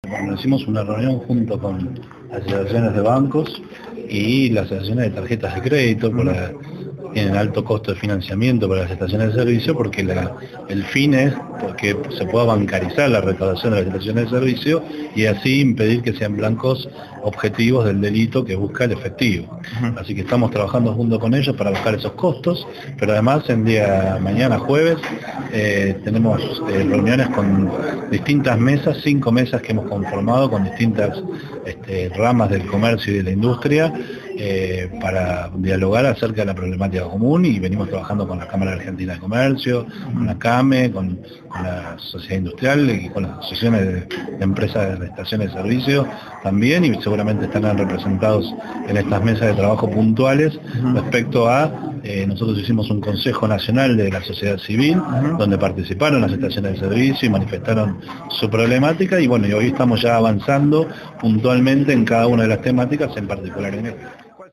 A su vez, el Secretario de Seguridad Interior, Gerardo Milman dijo que analizan el modo de reducir el efectivo en las expendedoras.